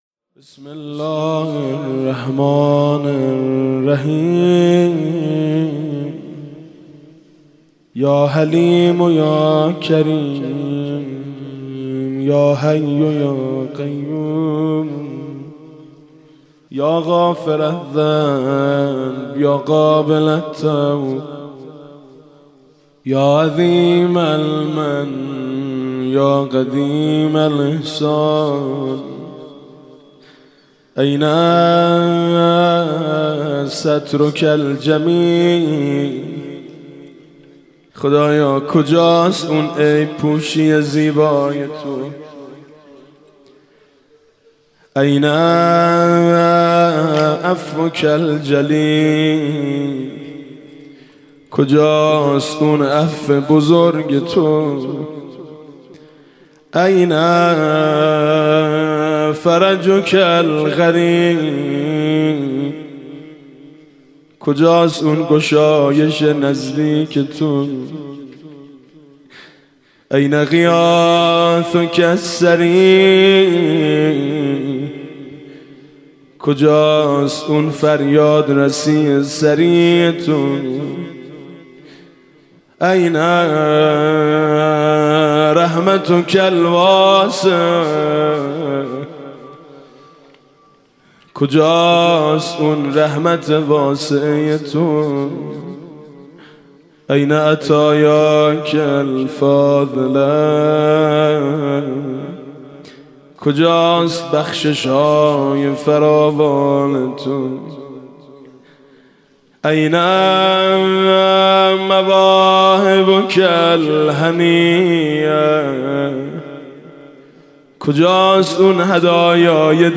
شب هشتم رمضان 96 - هیئت شهدای گمنام - فرازهایی از دعای ابوحمزه ثمالی